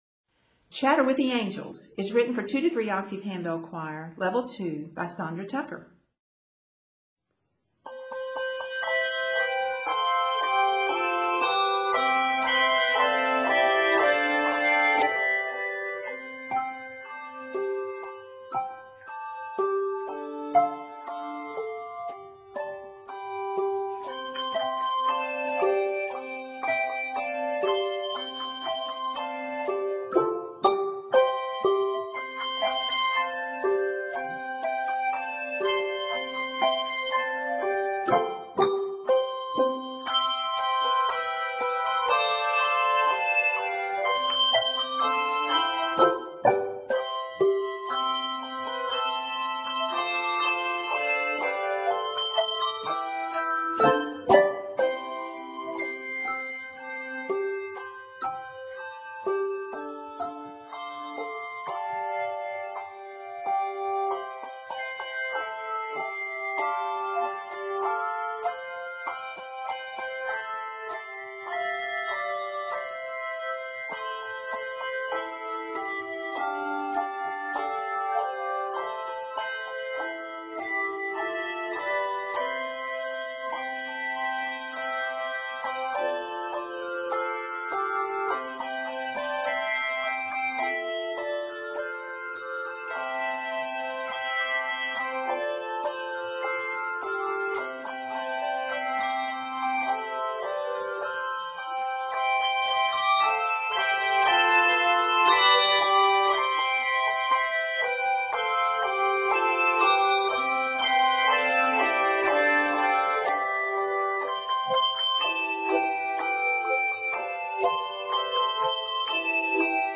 African-American Spiritual for 2-3 octave handbell choir